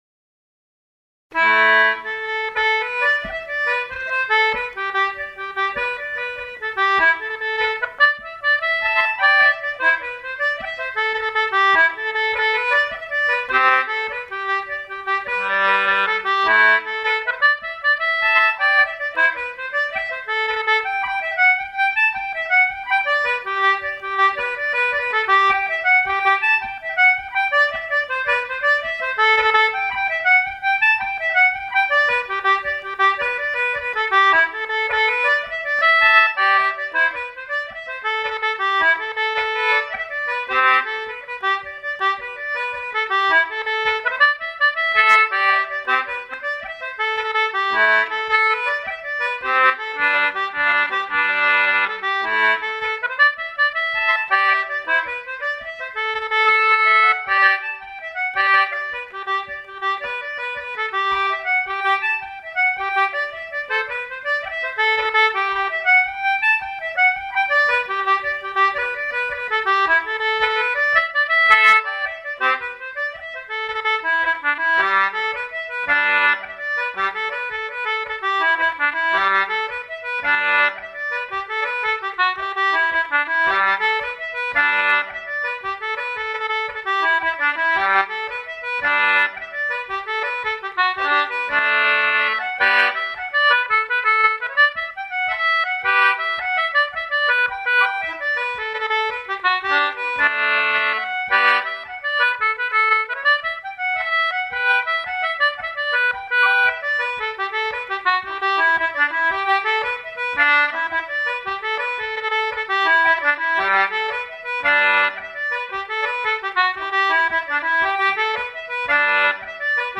plays a metal ended instrument, recorded informally at Gundagai August 2007 _____________________
twojigs.mp3